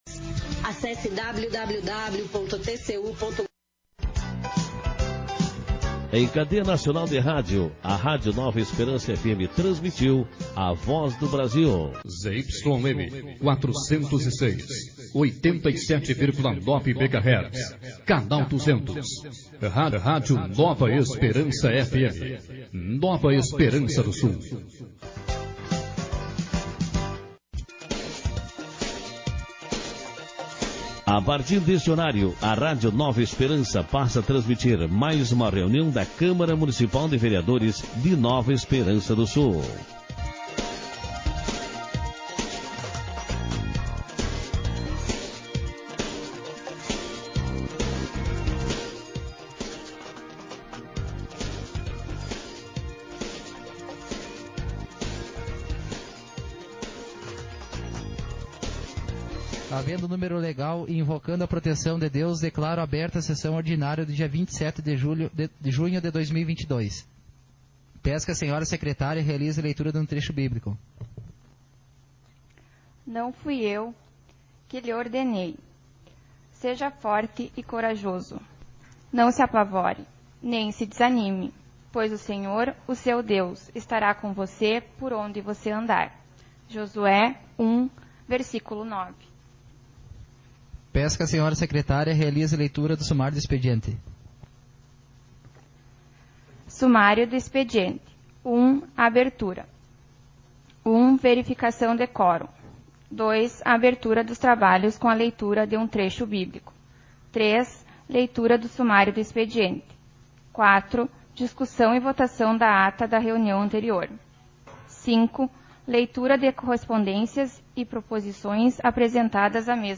Sessão Ordinária 19/2022